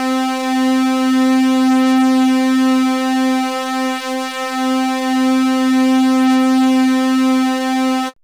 PWM 1 THICK.wav